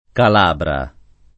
[ kal # bra ]